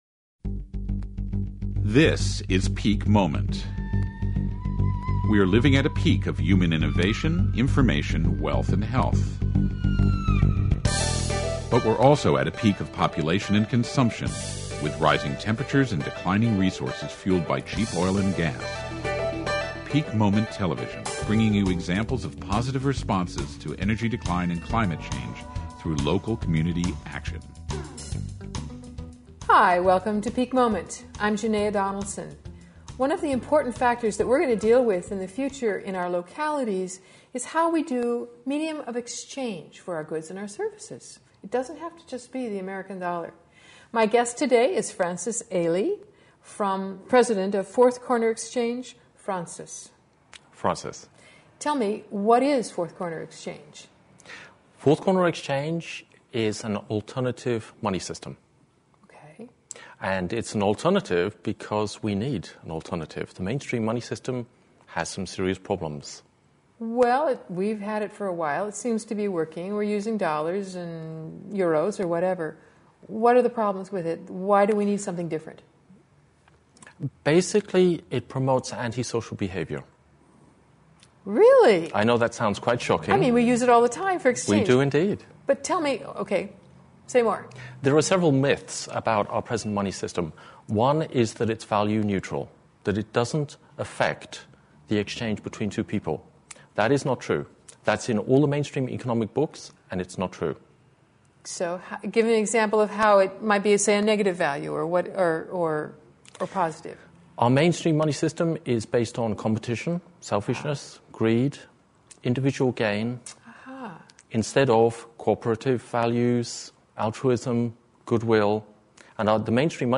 To frame this discussion, we are going to be spending some time, in particular, on the local currency movement. Listen to this interview with an activist from the local currency movement.